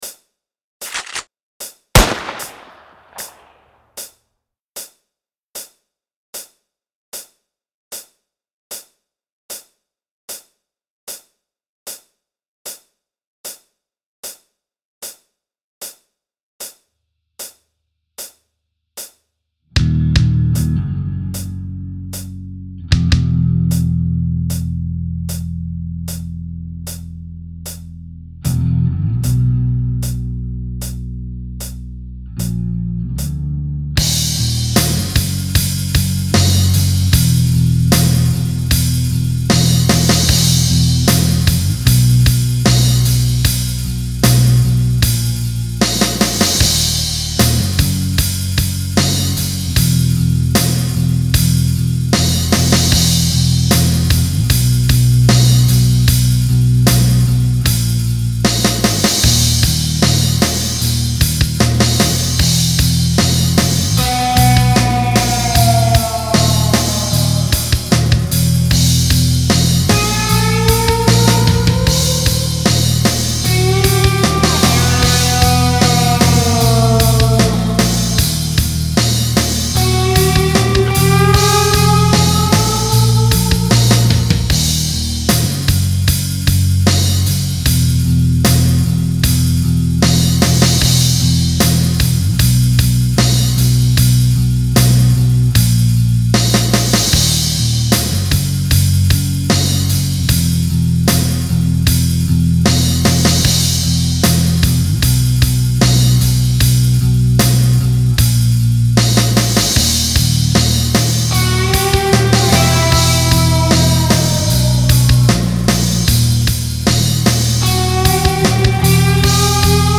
You be the main guitar part